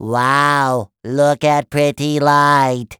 海绵宝宝说话音效免费音频素材下载